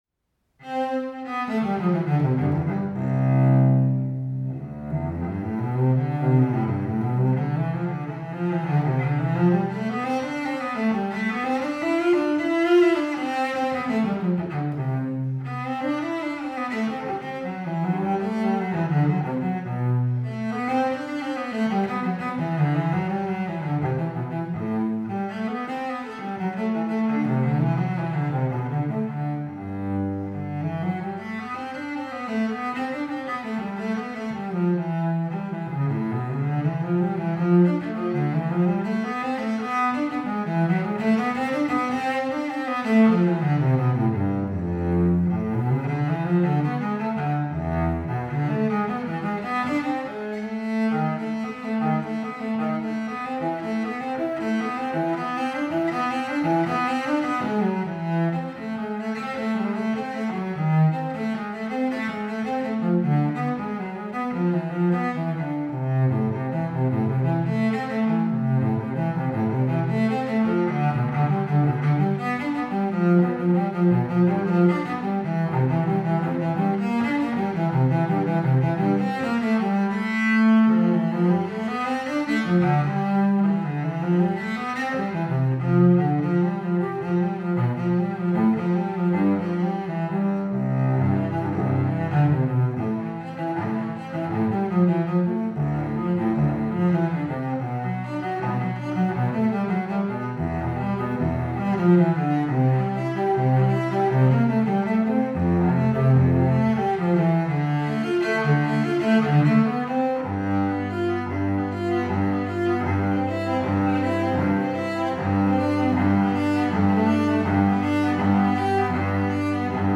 Violoncello solo